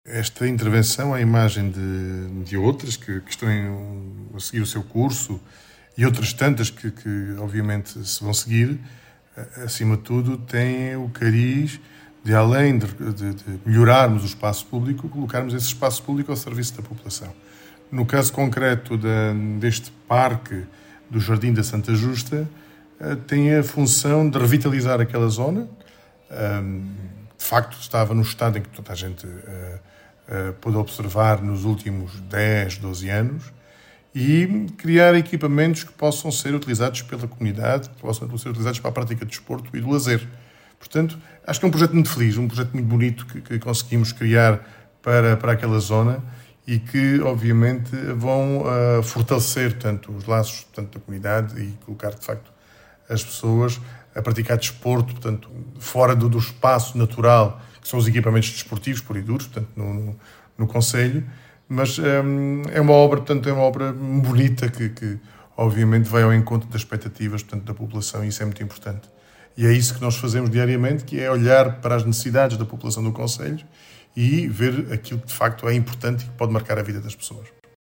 As explicações são de Álvaro Azedo, presidente da Câmara Municipal de Moura.